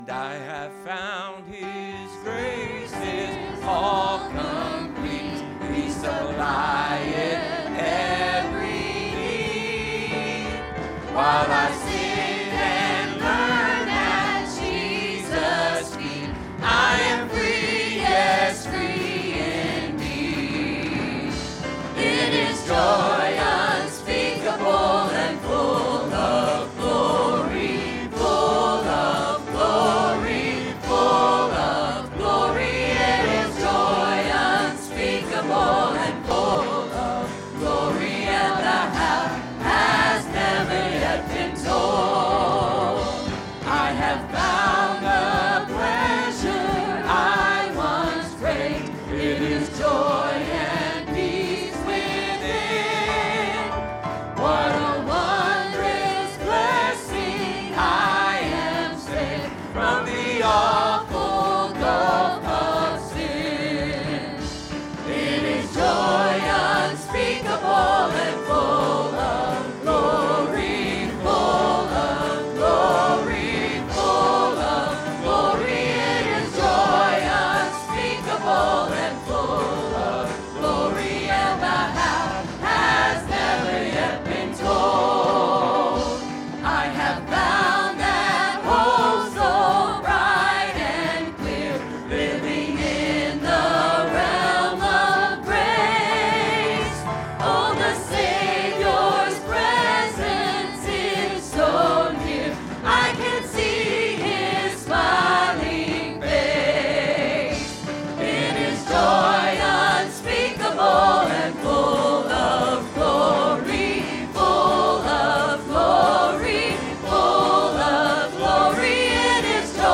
Sermons | Word of Life Pentecostal Church